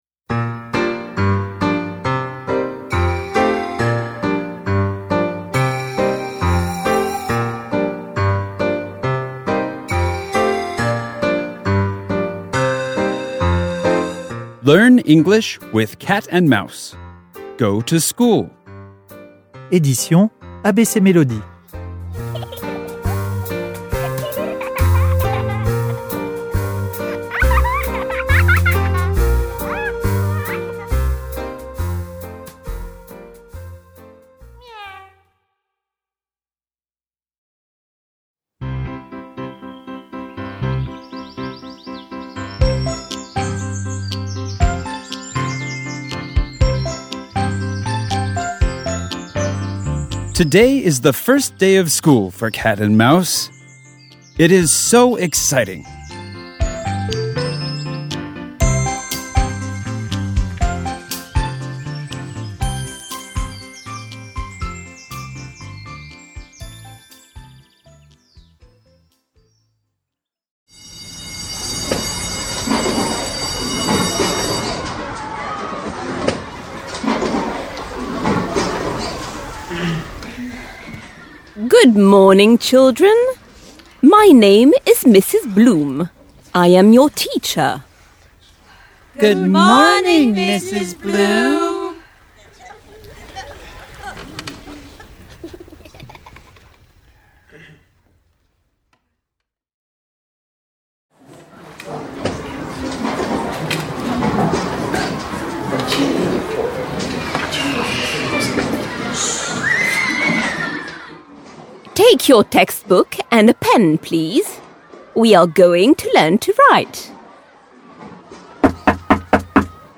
Sur le MP3 téléchargeable, retrouvez les dialogues en musique et un jeu audio pour bien retenir les mots et les phrases et bien prononcer !